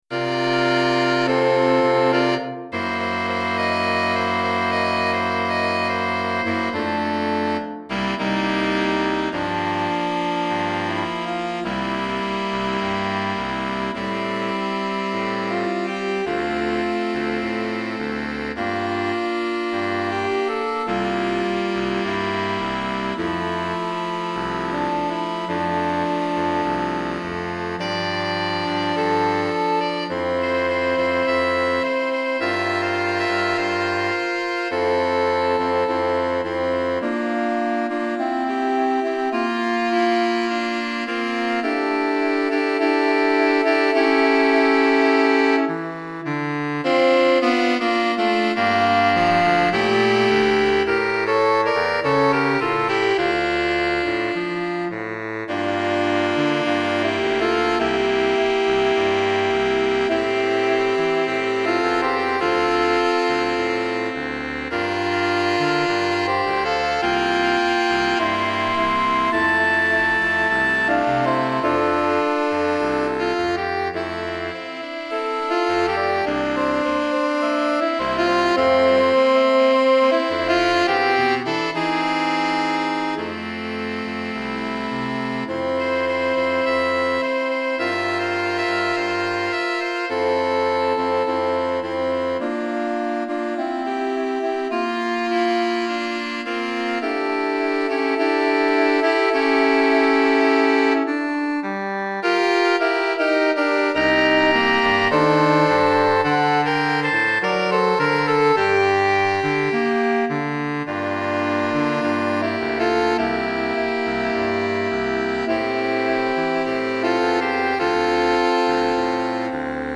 4 Saxophones